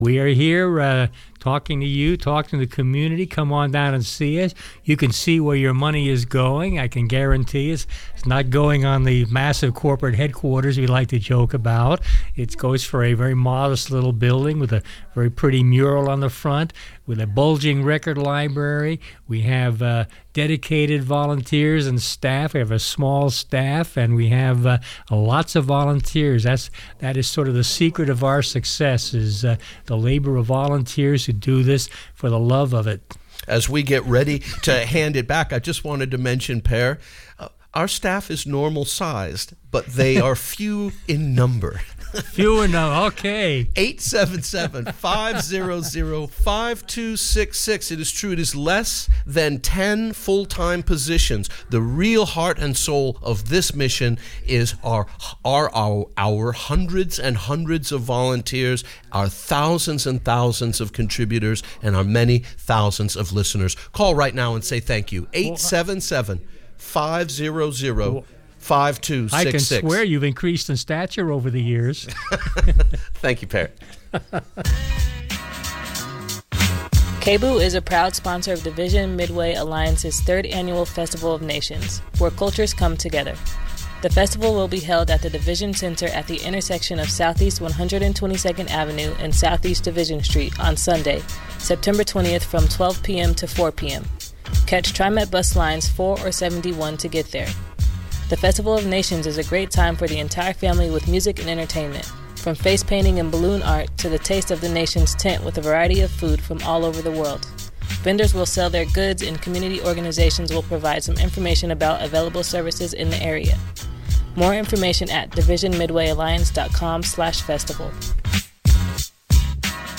Tune in to KBOO's Radiozine for intriguing Public Affairs programming Radiozine is your space to participate in the dialogue of conversation and opinion.